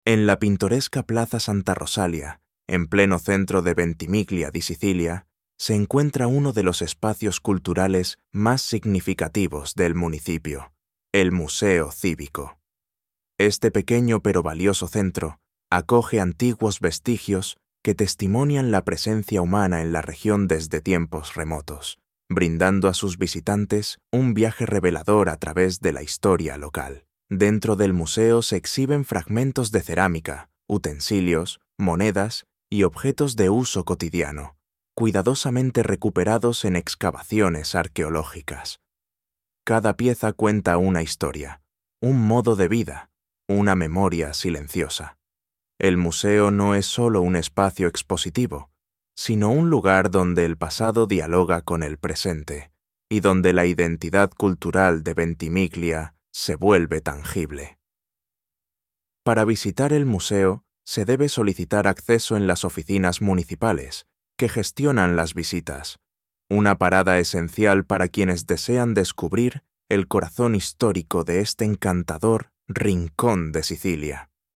Audio Guida